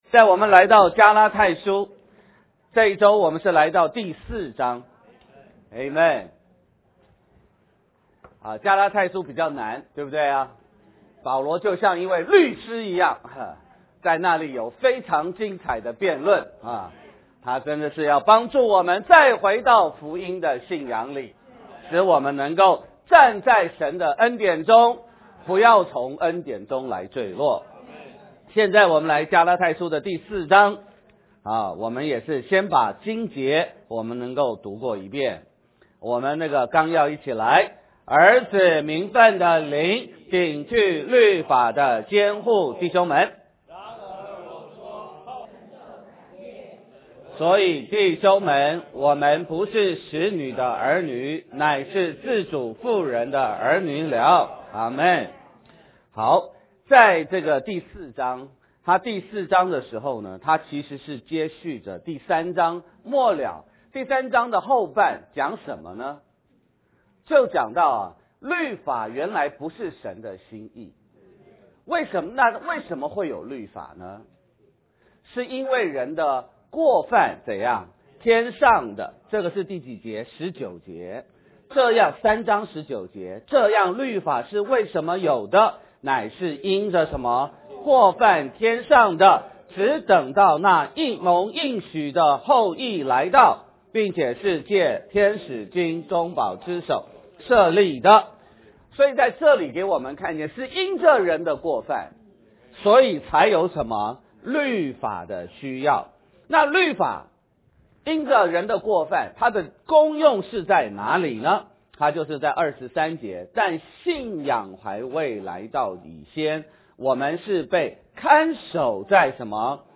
加拉太书第4章___读经示范.mp3